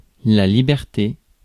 Ääntäminen
Synonyymit indépendance souveraineté libre arbitre franchise Ääntäminen France: IPA: [li.bɛʁ.te] Haettu sana löytyi näillä lähdekielillä: ranska Käännös Ääninäyte Substantiivit 1. freedom US 2. liberty US Suku: f .